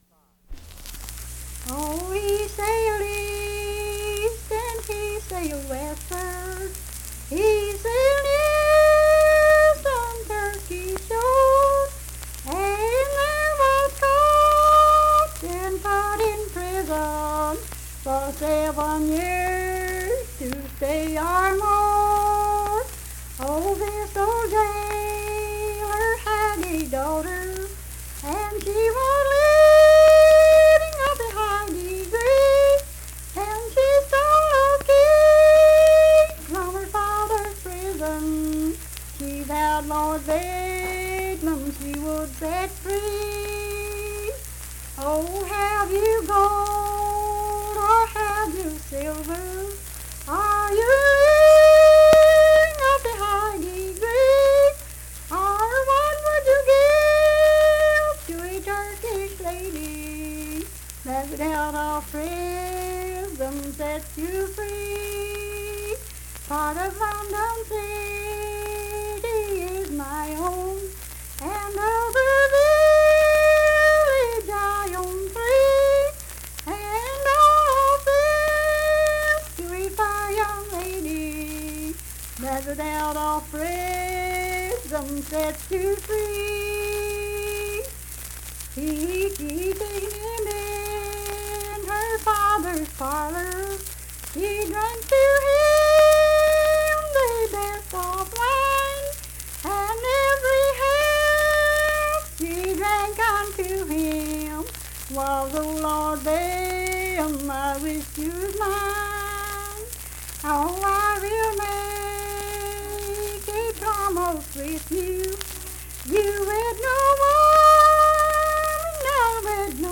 Unaccompanied vocal music
Verse-refrain, 9(4).
Voice (sung)
Mingo County (W. Va.), Kirk (W. Va.)